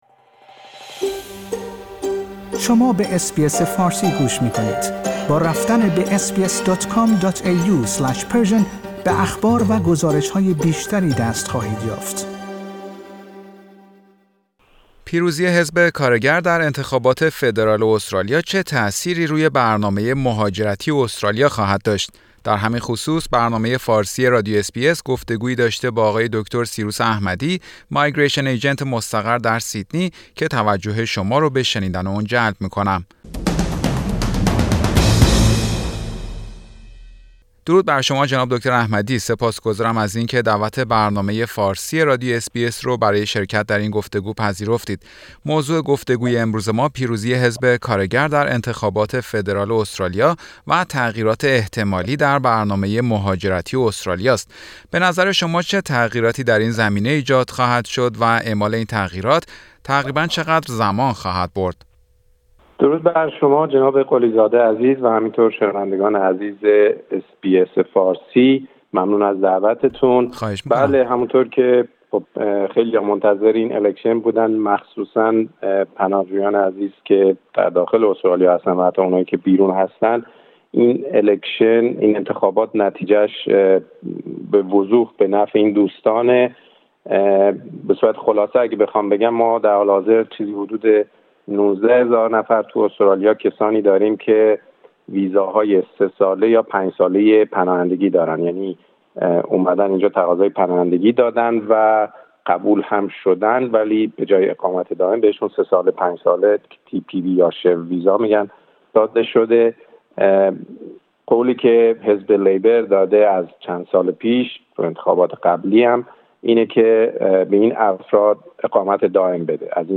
اطلاعات عنوان شده در این گفتگو، عمومی و کلی است.